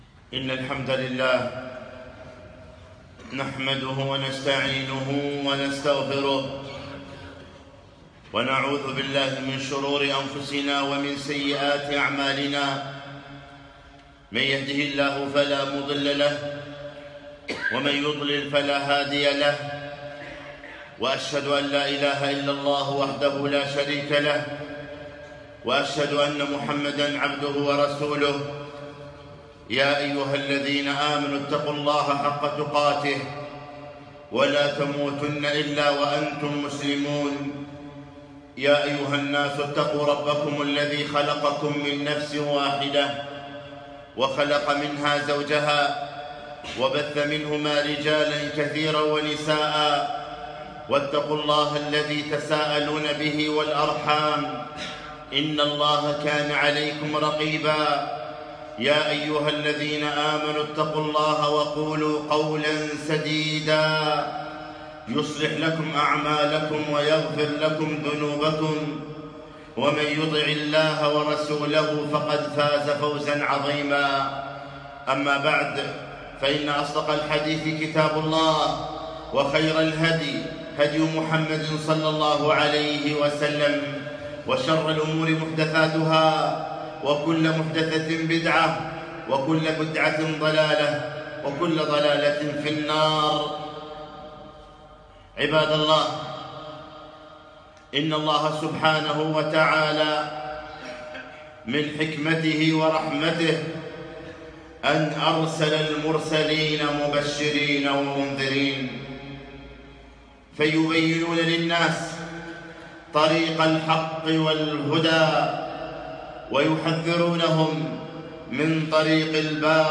خطبة - نفحات من دعوة نوح عليه السلام